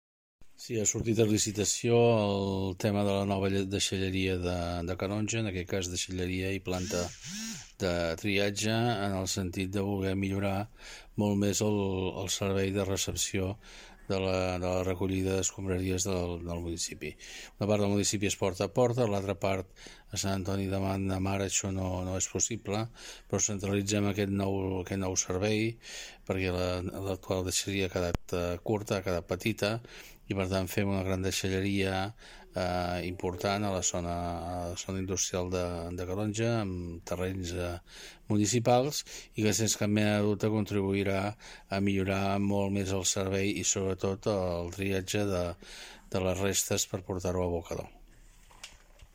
Jordi Soler, alcalde de Calonge i Sant Antoni, explica que des del consistori calongí volen millorar aquesta recollida i gestió de residus.